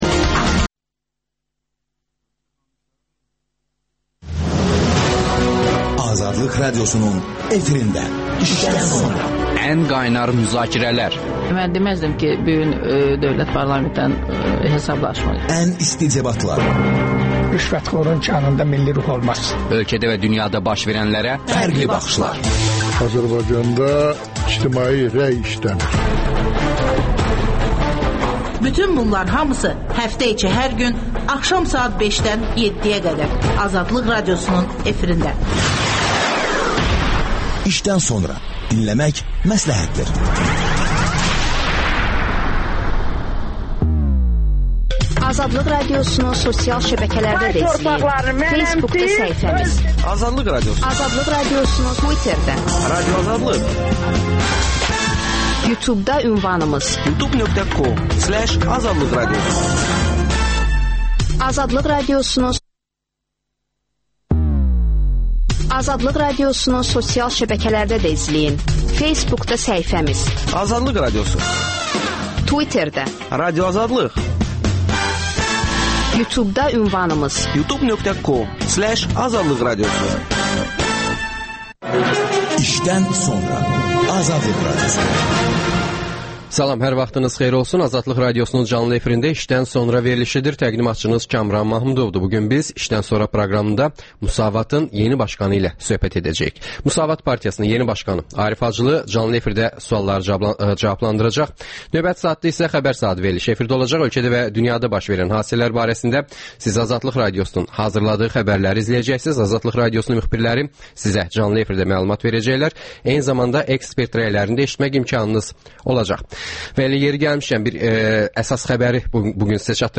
Müsavat Partiyasının yeni başqanı Arif Hacılı sualları canlı efirdə cavablandırır.